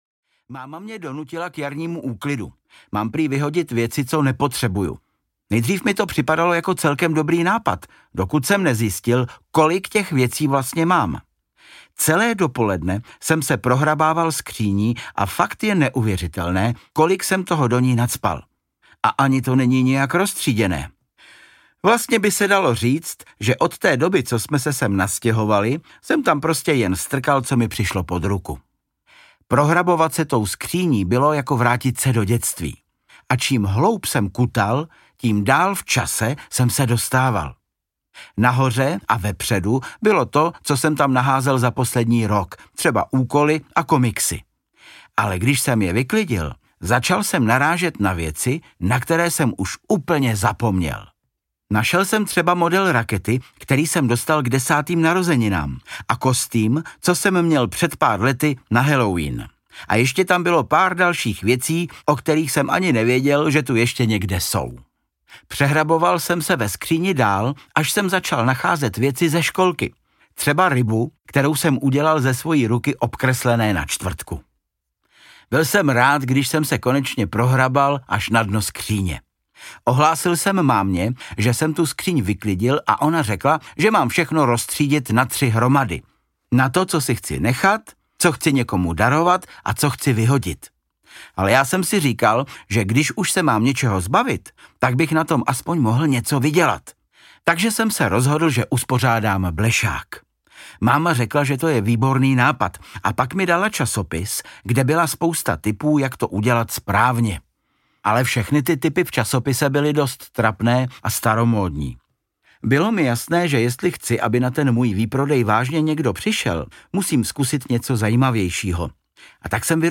Ukázka z knihy
• InterpretVáclav Kopta
denik-maleho-poseroutky-14-na-spadnuti-audiokniha